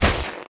drop_1.wav